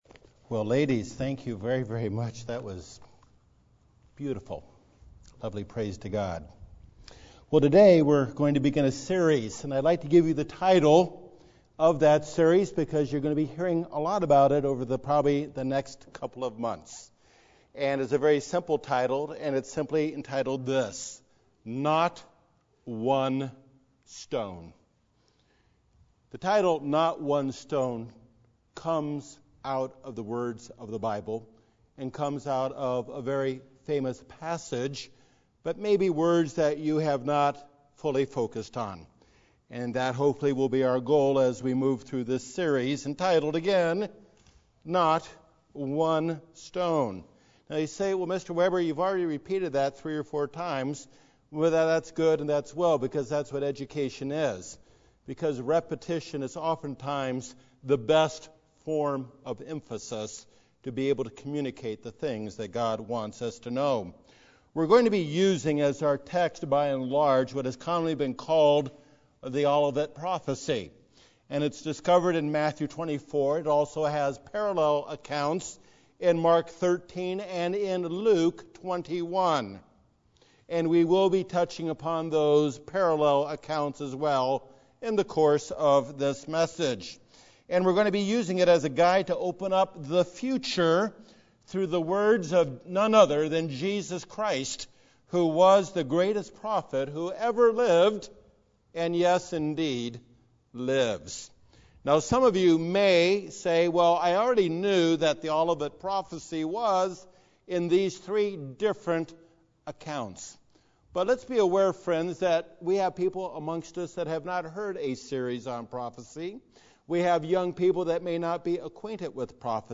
The introductory sermon of a 4-part series explaining the intent and purpose of prophecy, with a focus on Matthew 24 and its corollary accounts.